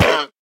Minecraft Version Minecraft Version snapshot Latest Release | Latest Snapshot snapshot / assets / minecraft / sounds / mob / llama / hurt3.ogg Compare With Compare With Latest Release | Latest Snapshot
hurt3.ogg